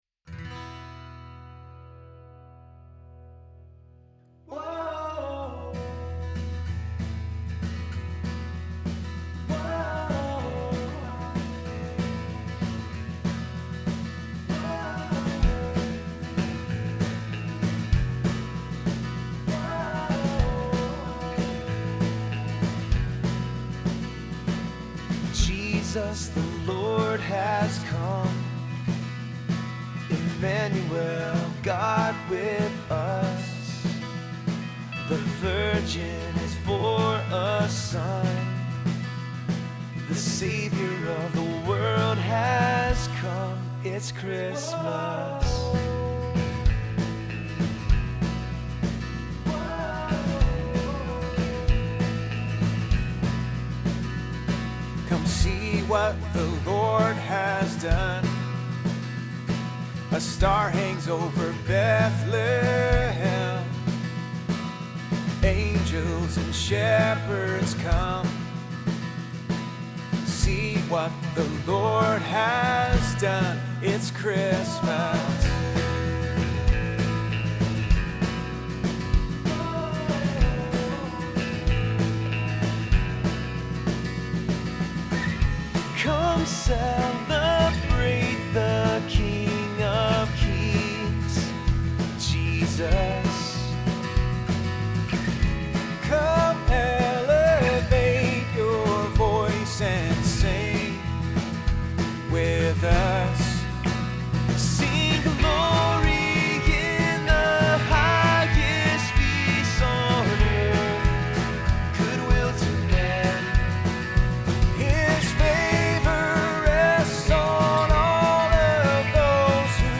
Christmas song
Obviously it needs to be LOUDER! I cranked out this mix hoping to bring the overall volume up through mastering, but I haven’t quite mastered the mastering yet, so I’m just uploading the mix.
vocals, backing vocals
drums
acoustic guitar, bass guitar, electric guitar, tambourine, vocals, backing vocals